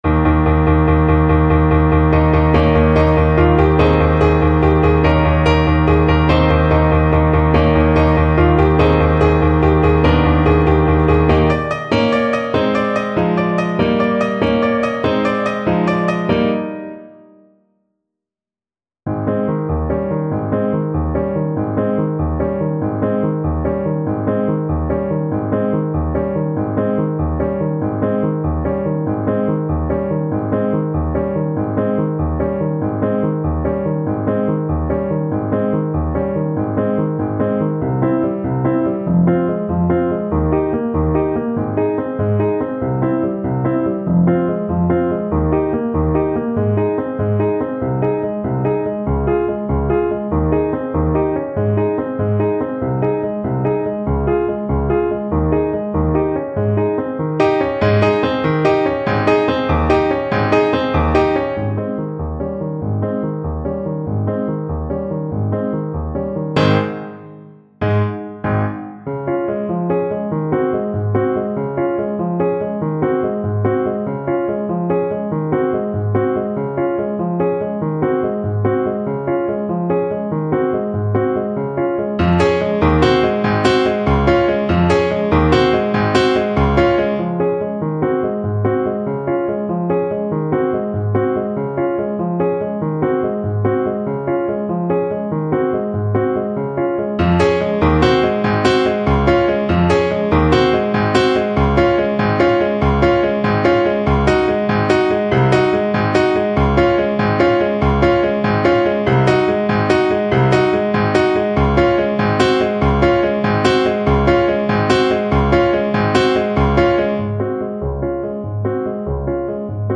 6/8 (View more 6/8 Music)
Ab major (Sounding Pitch) Bb major (Trumpet in Bb) (View more Ab major Music for Trumpet )
.=96 Allegro (View more music marked Allegro)